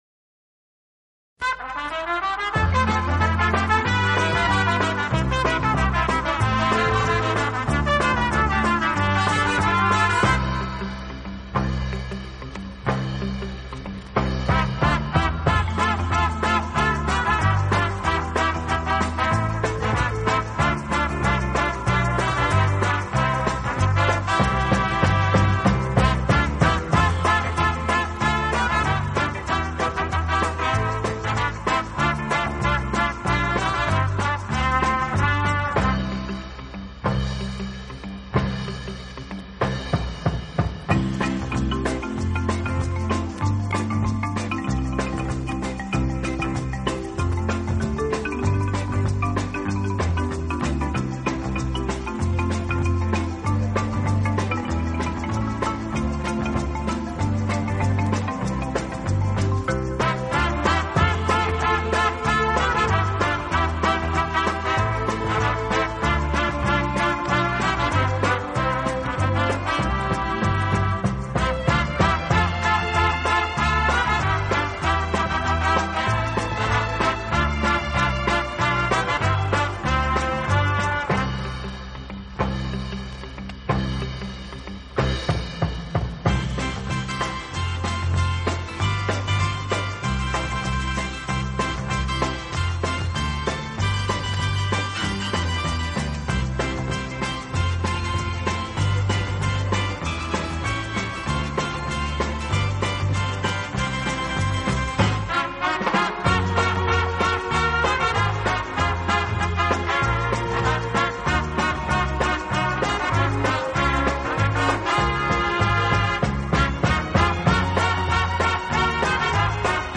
轻音乐